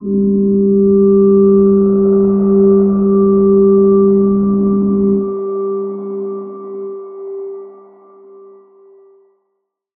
G_Crystal-G4-pp.wav